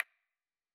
Sound / Effects / UI / Minimalist7.wav